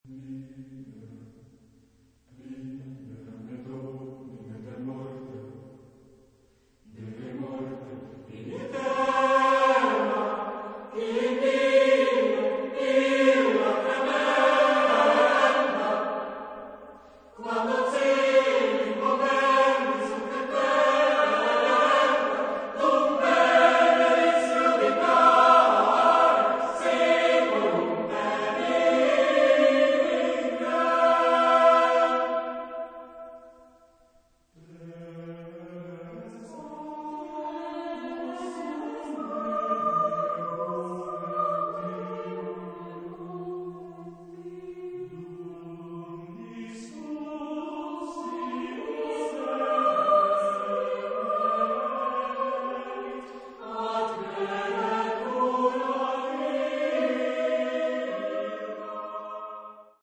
Genre-Style-Forme : Motet ; Sacré
Type de choeur : SSATB  (5 voix mixtes )
Tonalité : libre